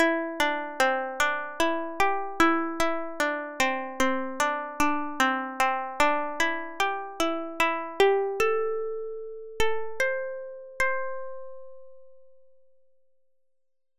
Mohana Ragam
A simple music generator that generates small pieces in carnatic music using Markov chains and random walks.
Further, it is supposed to sound good even without gamakams (slides+vibratos).
Ascent  : sa ri ga pa dha Sa